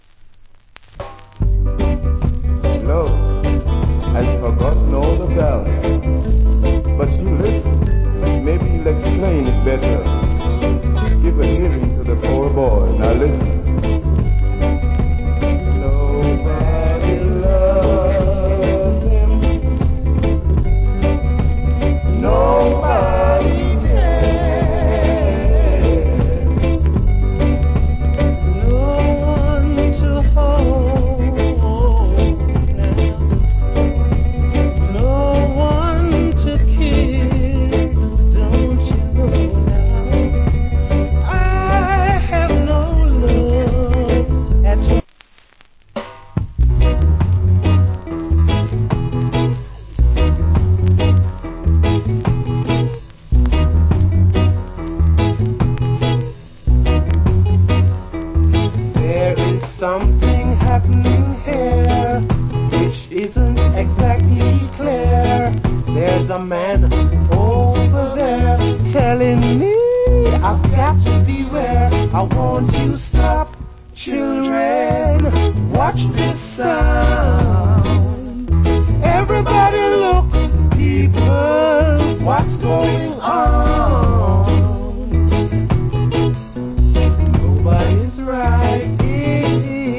Good Rock Steady Vocal.